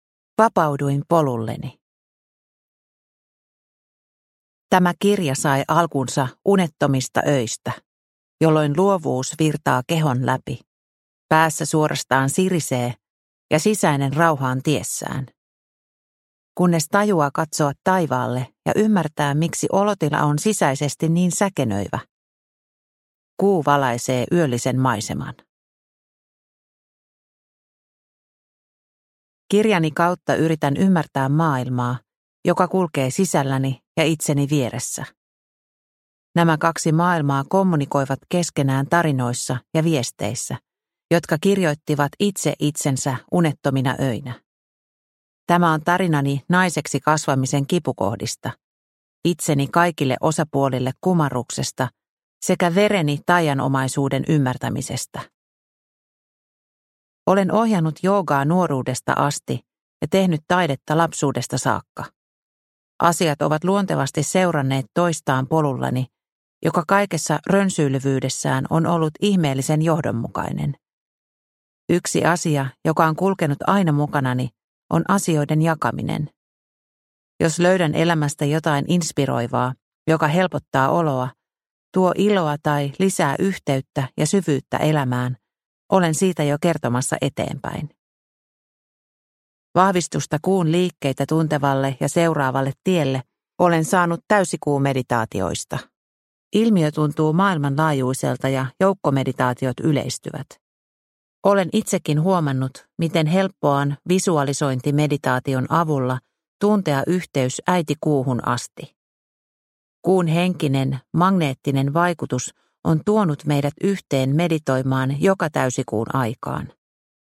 Lumoava nainen – Ljudbok